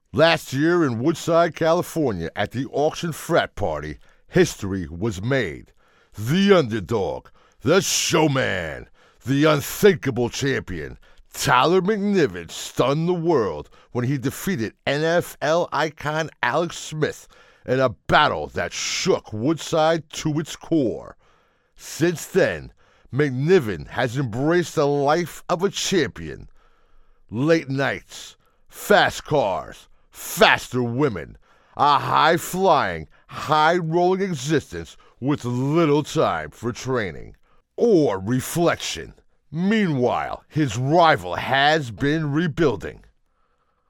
Voice is Deep, Distinctive, and Authoritative, with a Commanding Presence that exudes Confidence and Power.
Live Announcer
U F C Announcement